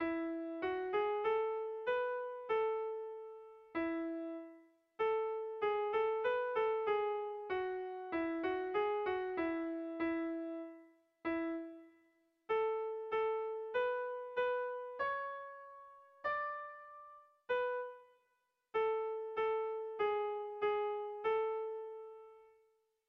Air de bertsos - Voir fiche   Pour savoir plus sur cette section
Kontakizunezkoa
Doinu alemana omen da Azkuek dionez.
AB